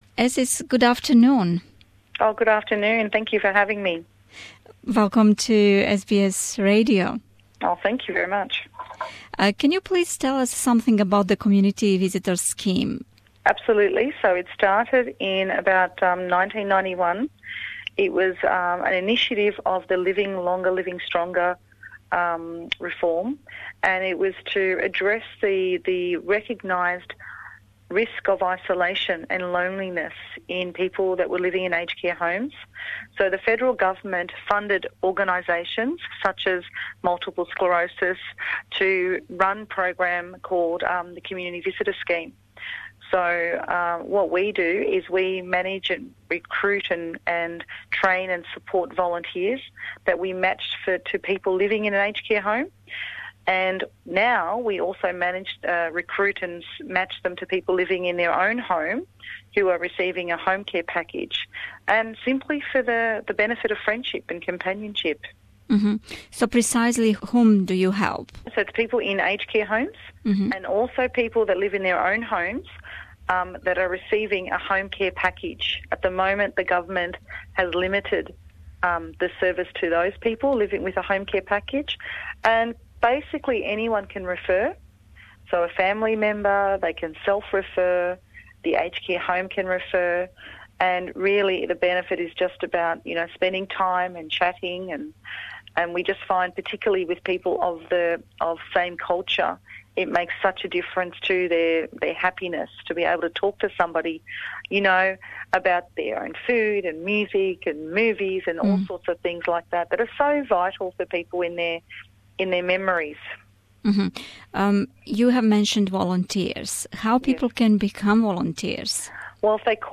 (Интервју је на енглеском а прилог који је повезан линком испод слике је на српском)